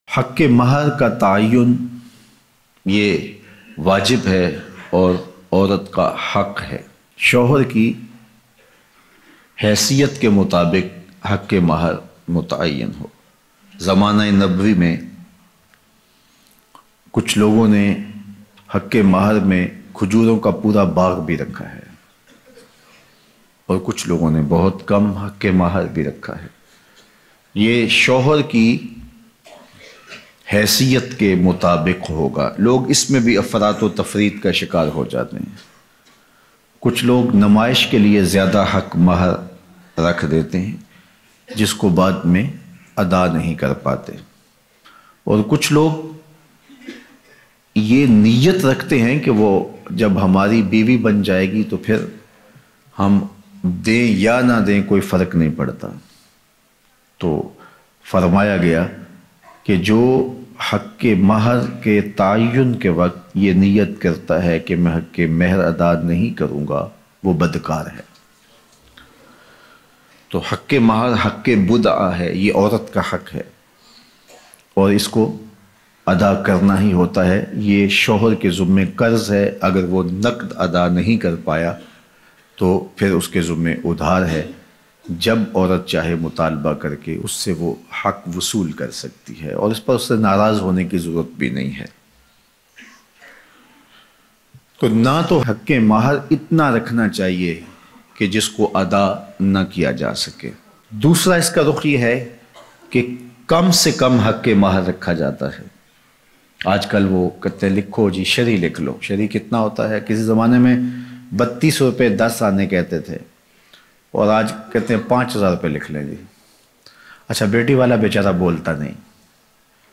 Haq-e-Mehar kya hai Kitna hona chahye Bayan Mp3